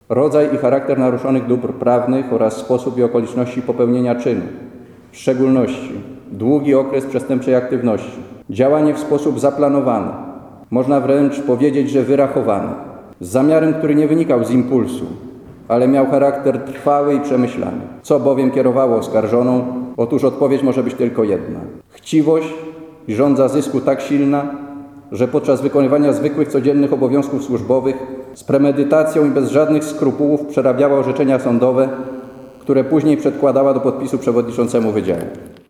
prokurator.mp3